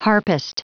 Prononciation du mot harpist en anglais (fichier audio)
Prononciation du mot : harpist